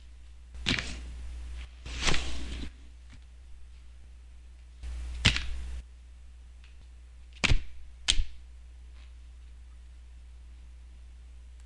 playing cards » shuffle2
描述：Shuffling cards in two hands.
标签： environmentalsoundsresearch cards deck playingcards shuffle
声道立体声